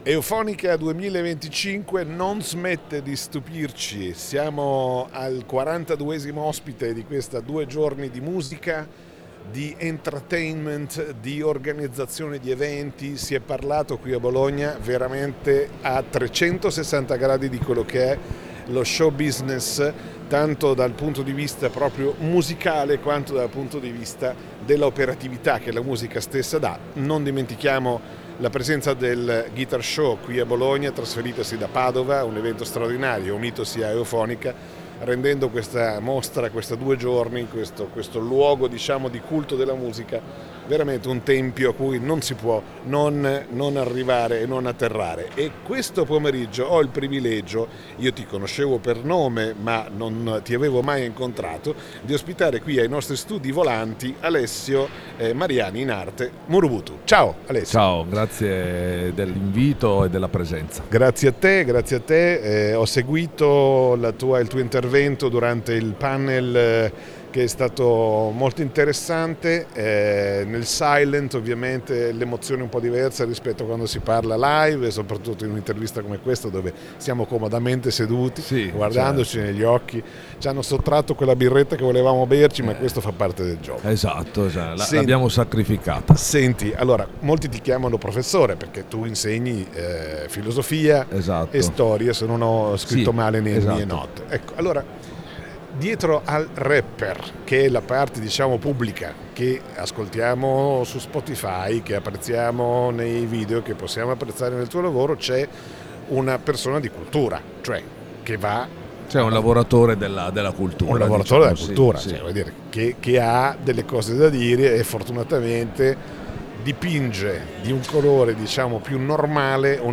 Intervista a Murubutu
Intervista a Murubutu nel nostro spazio Eufonica